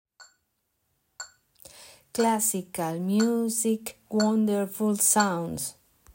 Rhythm and words
They contain phrases pronounced imitating the scores on the second column.